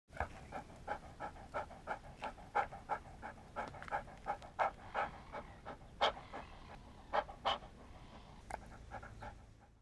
دانلود آهنگ سگ 11 از افکت صوتی انسان و موجودات زنده
جلوه های صوتی
دانلود صدای سگ 11 از ساعد نیوز با لینک مستقیم و کیفیت بالا